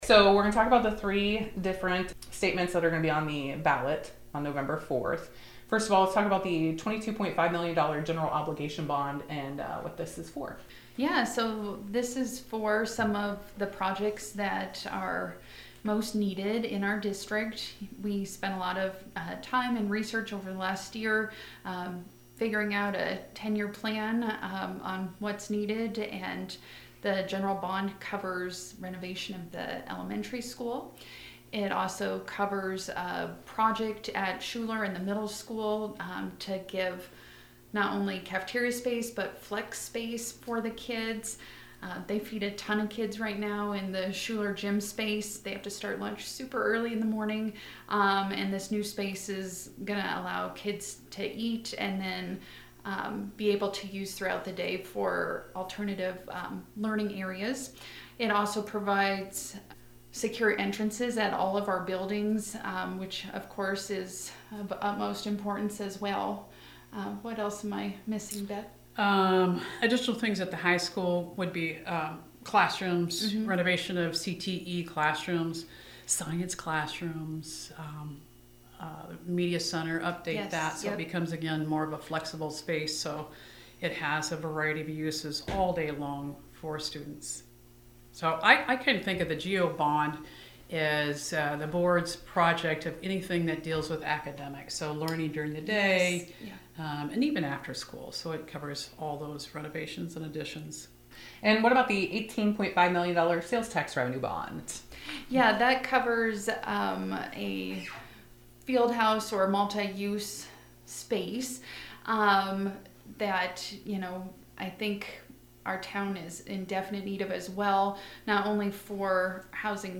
atlantic-school-bond-measures-explained.mp3